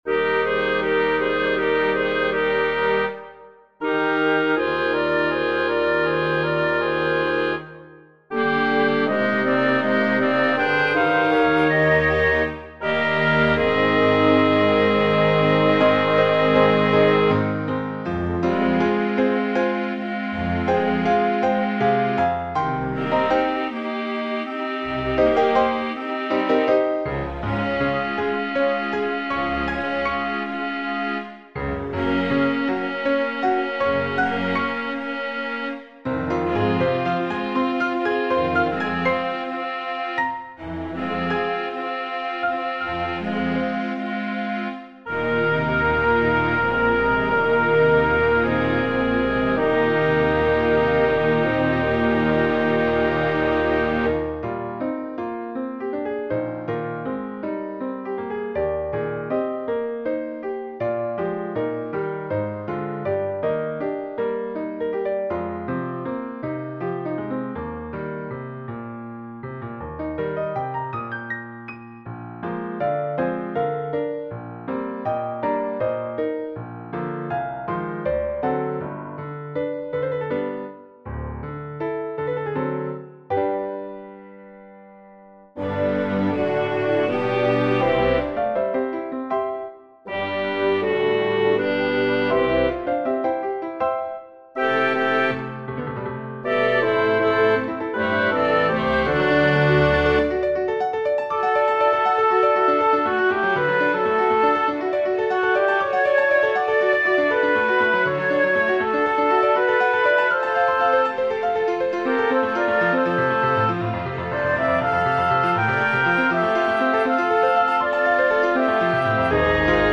These scores are typically large, orchestral works.